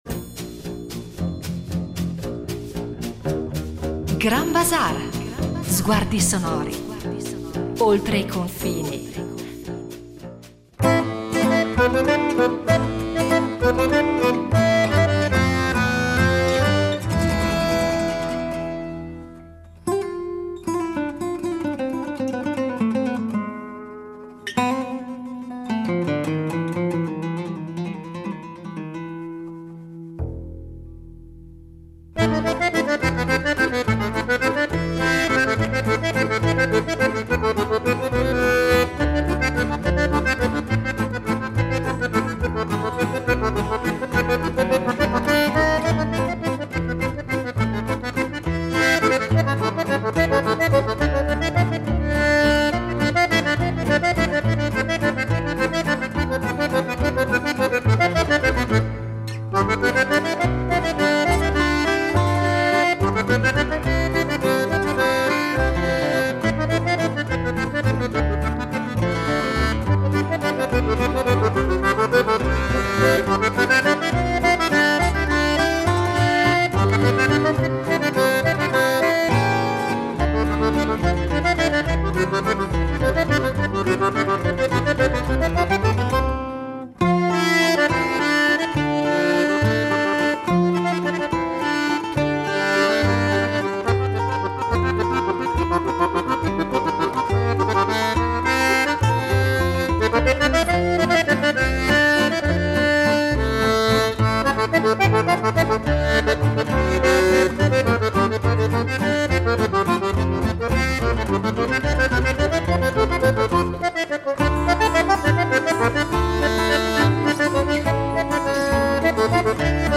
attraverso le sue parole e i suoni dei suoi dischi.